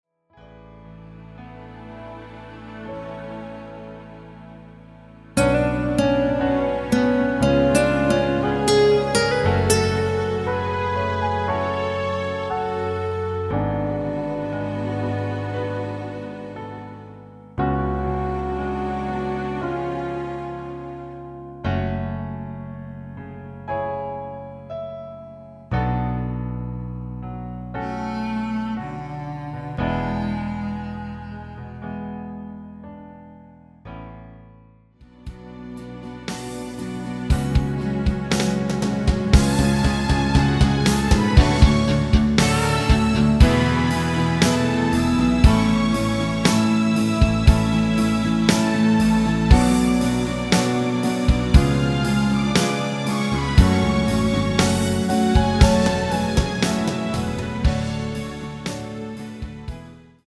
F#m
앞부분30초, 뒷부분30초씩 편집해서 올려 드리고 있습니다.
중간에 음이 끈어지고 다시 나오는 이유는